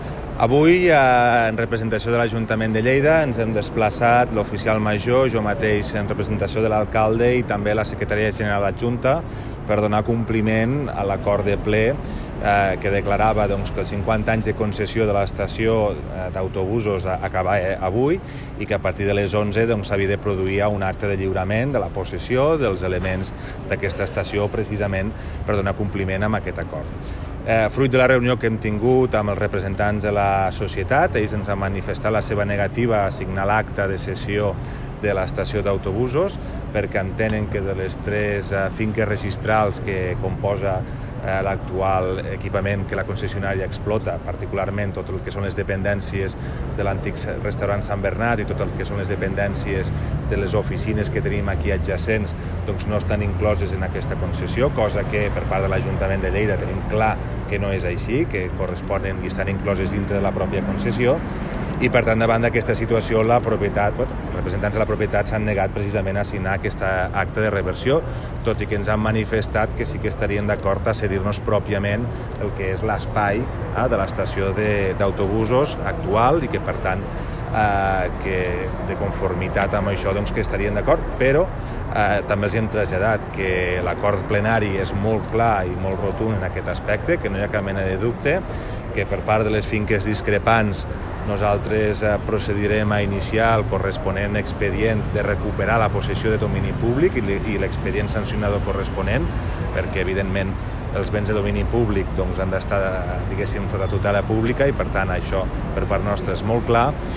tall-de-veu-del-primer-tinent-dalcalde-toni-postius